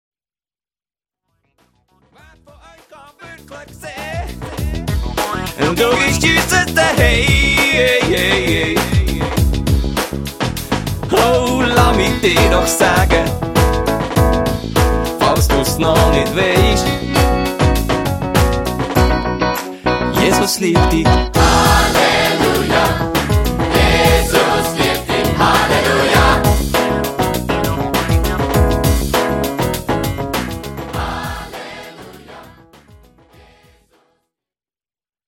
Songs (Lead Vocals)